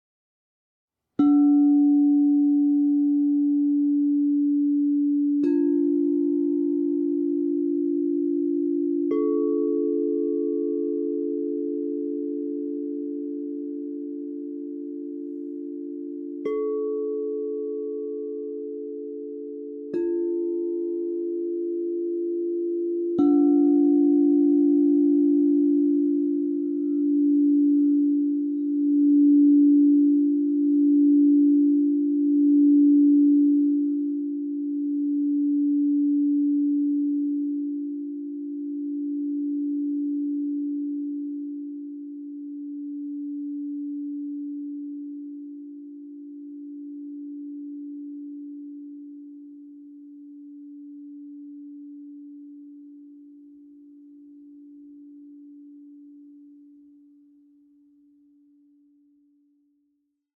Meinl Sonic Energy 3-piece Crystal Singing Bowl Set, A4 / F4 / D4, 432 Hz (CSBSETAFD)
The white-frosted Meinl Sonic Energy Crystal Singing Bowls made of high-purity quartz create a very pleasant aura with their sound and design.
Their long-lasting, spherical tone spreads when the singing bowl is gently tapped or rubbed so that the energy can be felt in the entire surroundings.